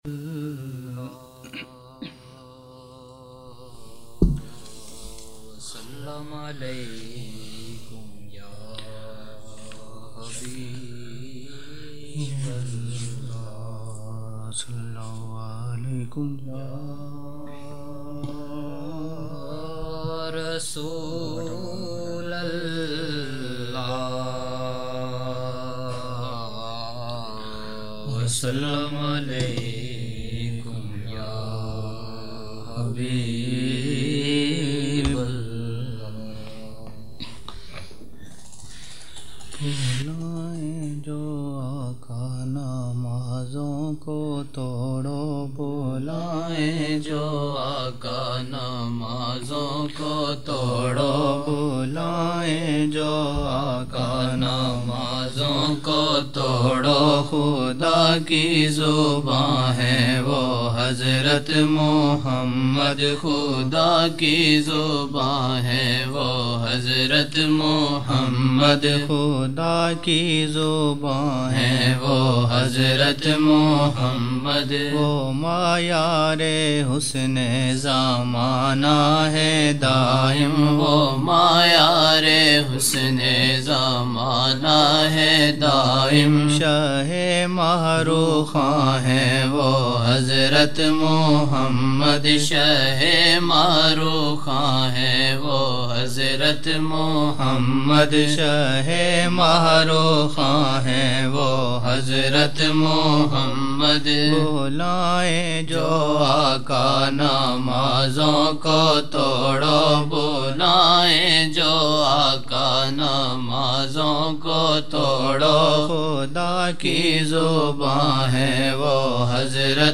10 November 1999 - Wednesday Maghrib mehfil (2 Shaban 1420)
Majmoa e Naat Shareef